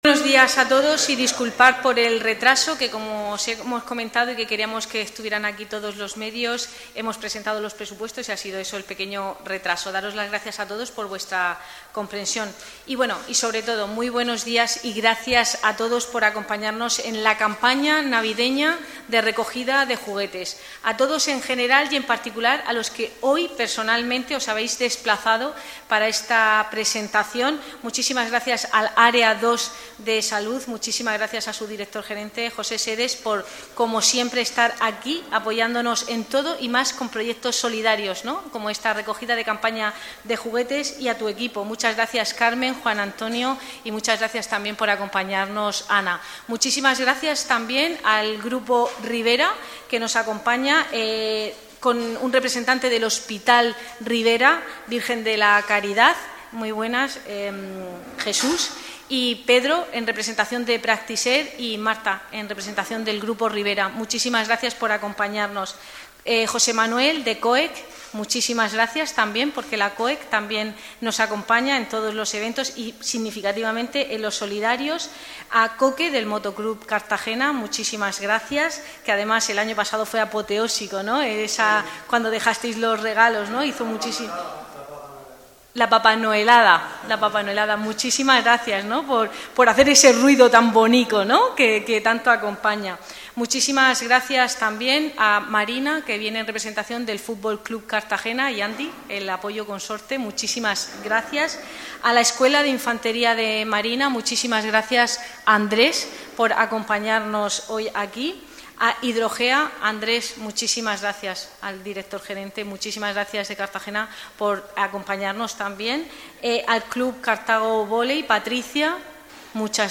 Enlace a Presentación de la campaña de recogida de juguetes por Cristina Mora, concejala de Servicios Sociales